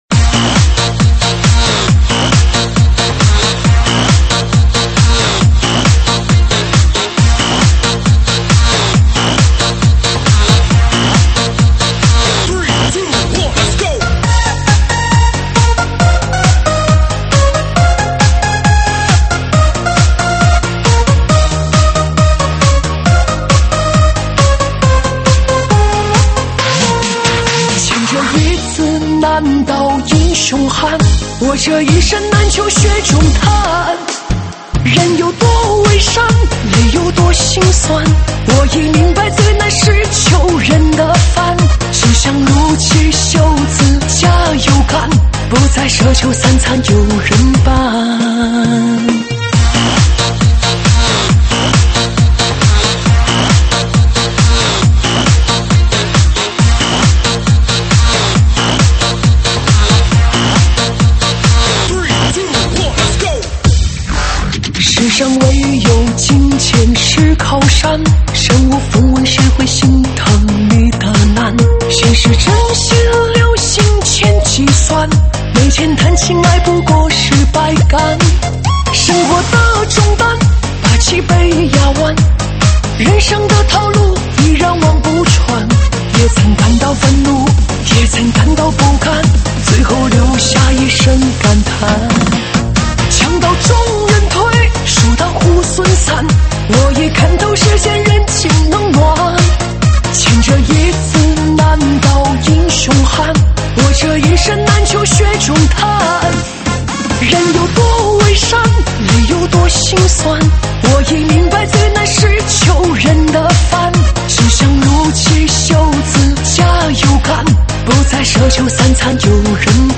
[现场串烧]
舞曲类别：现场串烧